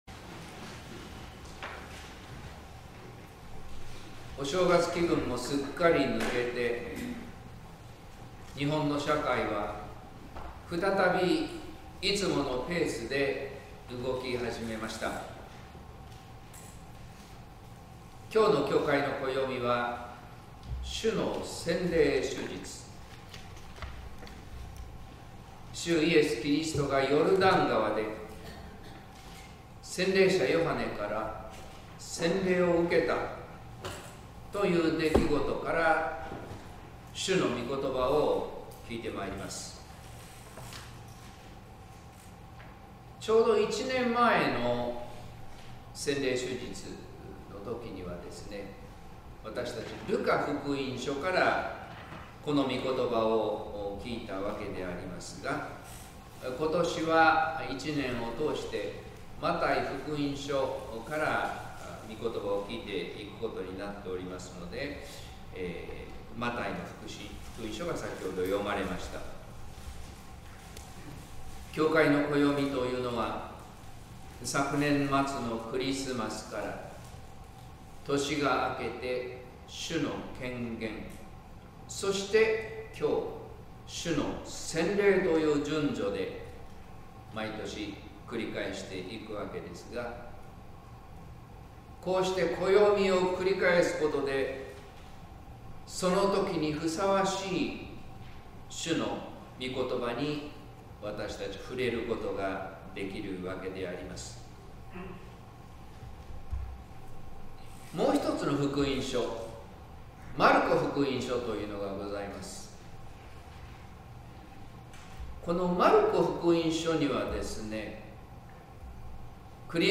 説教「選ばれ、喜び迎えられ」（音声版） | 日本福音ルーテル市ヶ谷教会